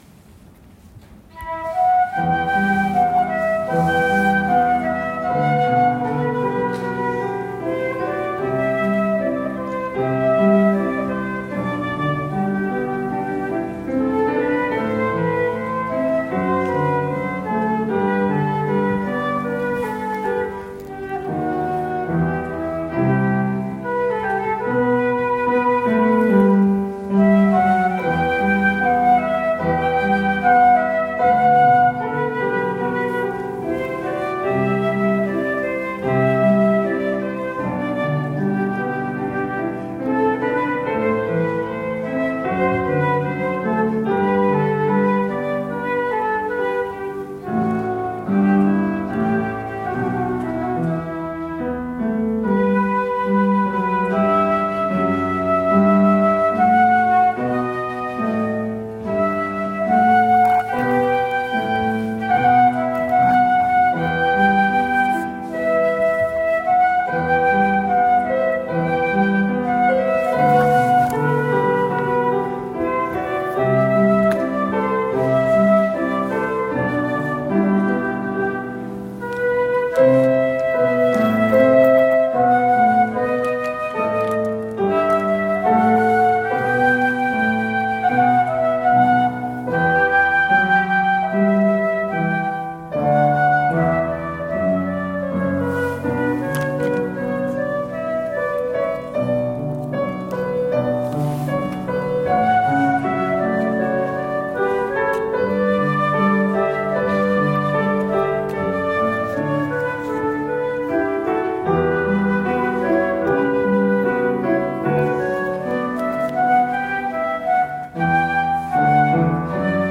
教会日記 : クリスマス音楽祭
フルート
ピアノ
♪ テレマンの「マニフィカート（わが魂は主をあがめ）」よりアリア(フルート＆ピアノ)